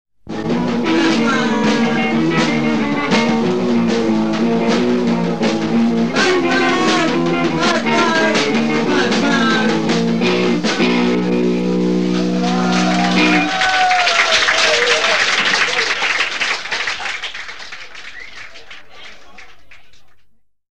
Guitar, Voice
Drums
Bass
Organ, Synthesizer